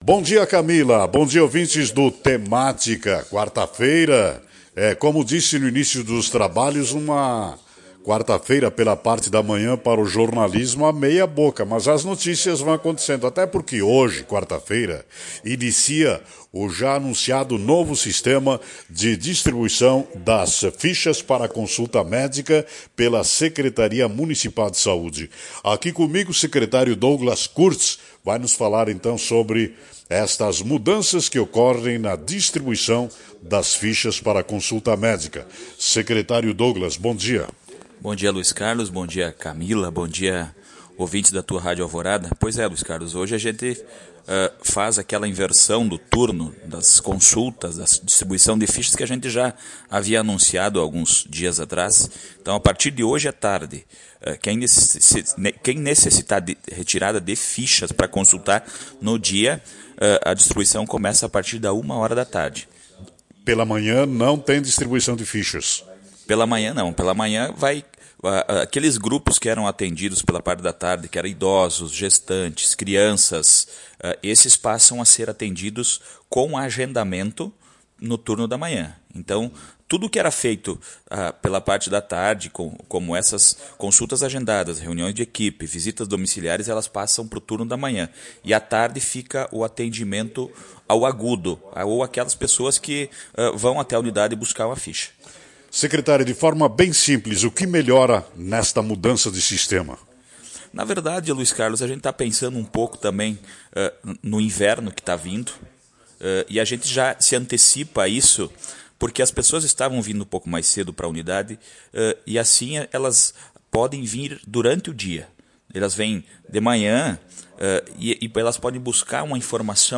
Ouça a entrevista com o secretário na íntegra.